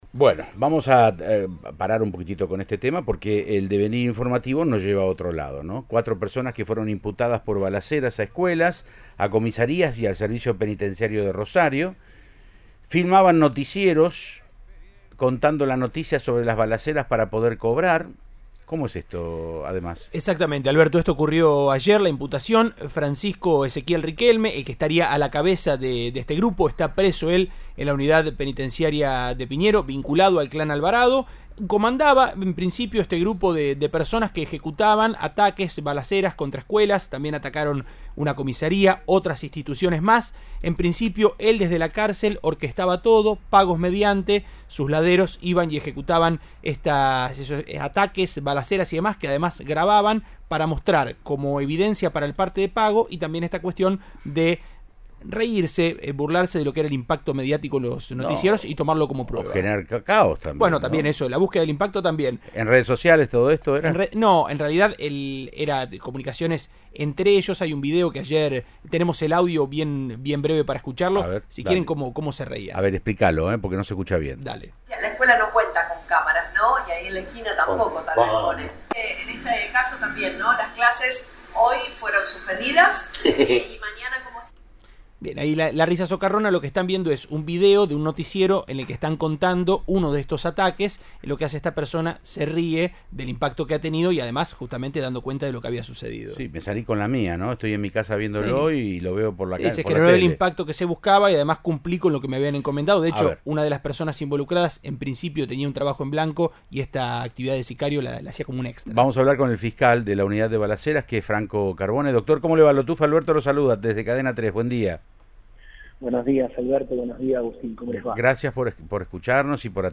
El fiscal Franco Carbone, del Equipo para el Abordaje de Delitos Cometidos con Armas de Fuego, dialogó con Siempre Juntos de Cadena 3 Rosario y brindó detalles de la investigación.